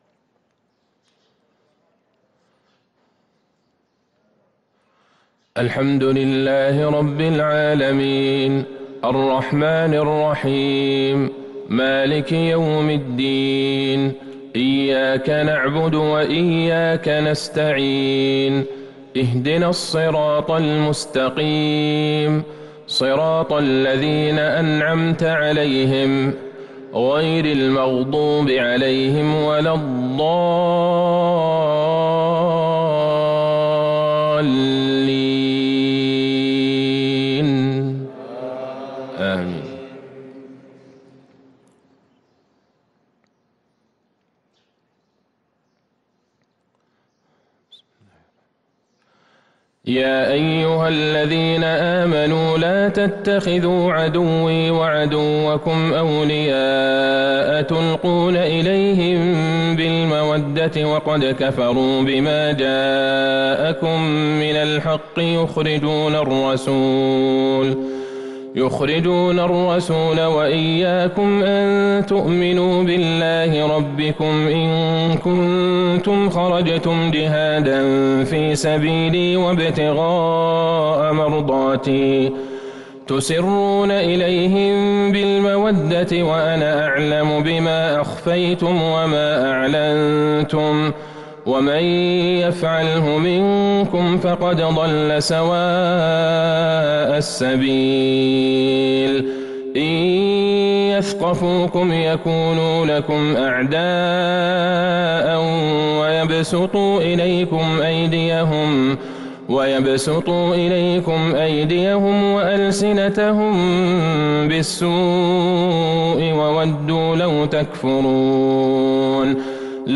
صلاة الفجر للقارئ عبدالله البعيجان 5 ربيع الآخر 1443 هـ
تِلَاوَات الْحَرَمَيْن .